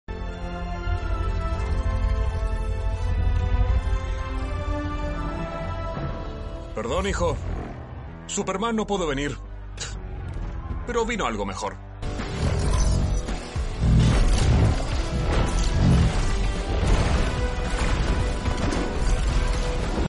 Mini demo de voz Guy sound effects free download
Guy Gardner Superman 2025 Doblaje Fandub latino